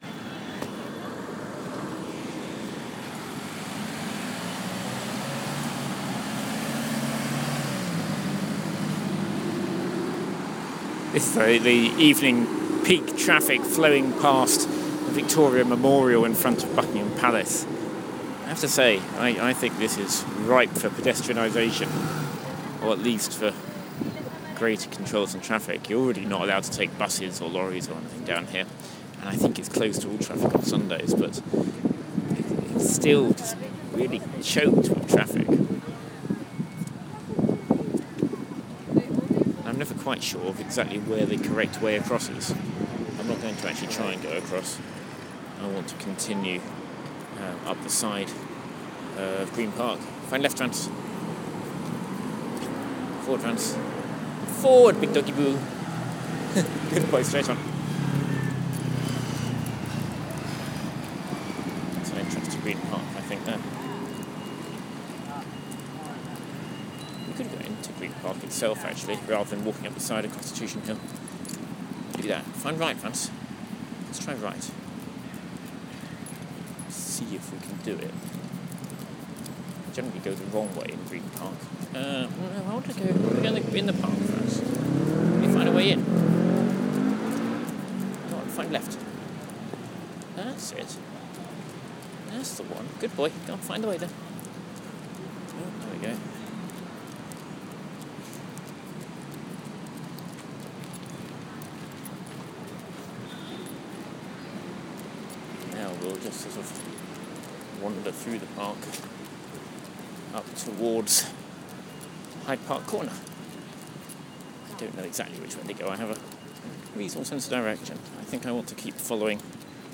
We cross the end of The Mall opposite Buckingham Palace and head up a path inside The Green Park.